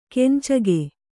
♪ kencage